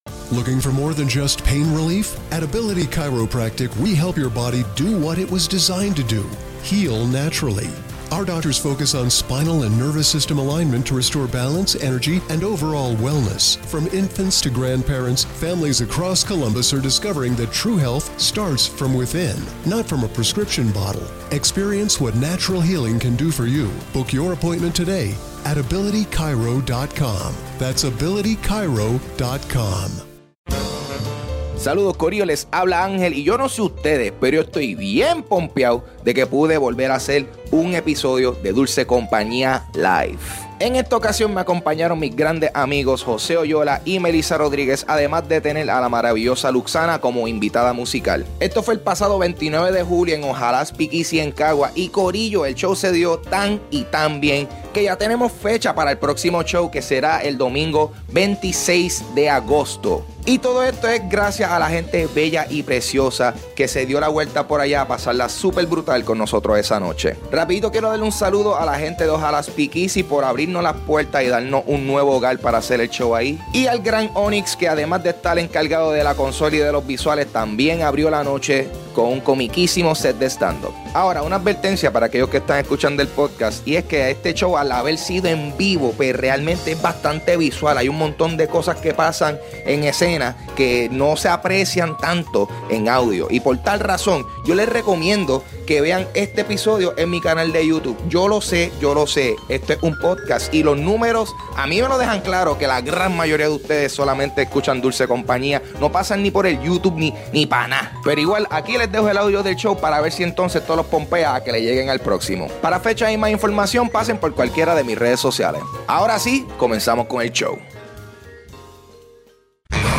Grabado en vivo en Ojalá Speakeasy en Caguas julio 29 de 2018.